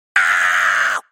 人类的声音 " 僵尸怪物的叫声
Tag: 尖叫 尖叫 呼喊 尖叫 可怕的 恐怖的 大呼小叫